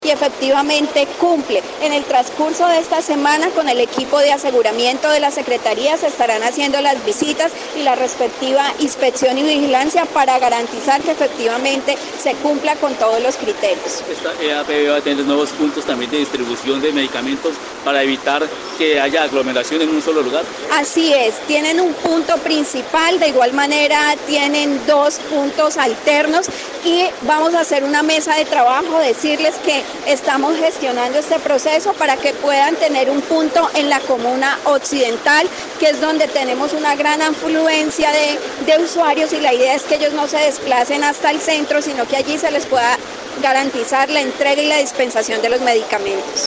Mallerly González Arias, secretaria de salud departamental, dijo que, la droguería viene prestando de manera normal su servicio, y se han comprometido con la instalación de dos nuevos puntos, uno de ellos en la comuna occidental, esto, para evitar la aglomeración de usuarios.